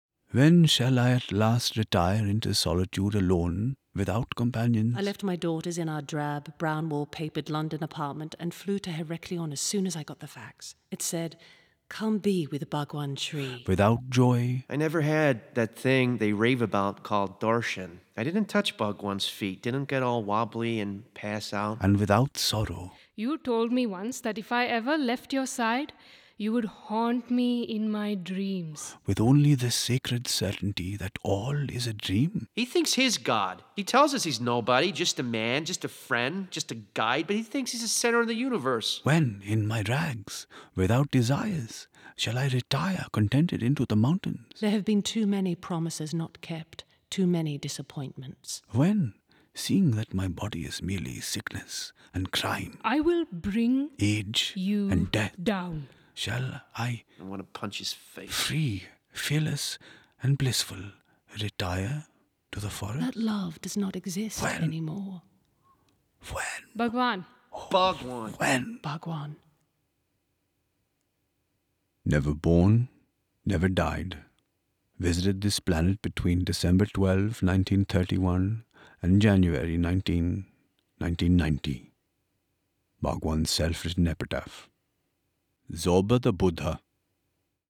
Zorba-The-Buddha-Trailer.mp3